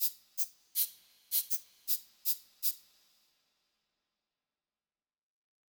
rythmic_chardi_arpeggio.wav